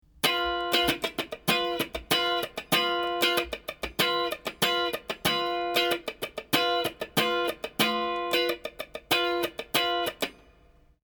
カッティングしているだけのフレーズが①、カッティングにスライド・ブラッシングを加えて弾いているのが②です。